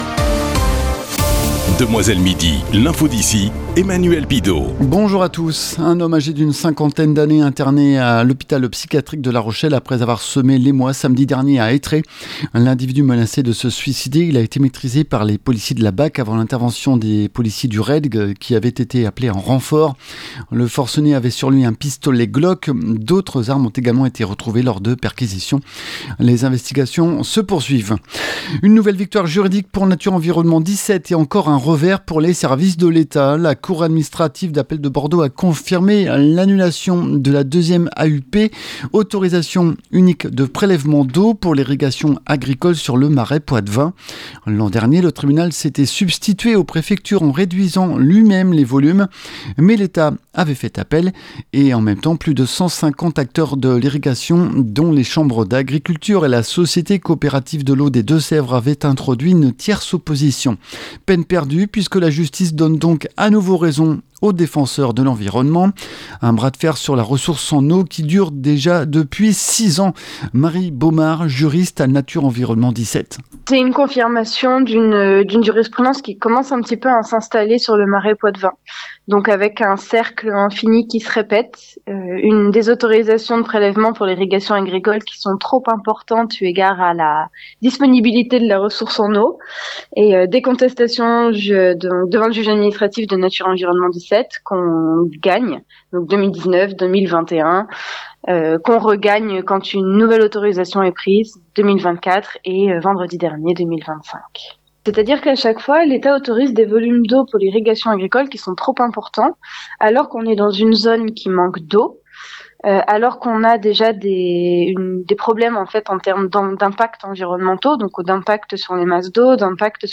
Le Journal du 30 septembre 2025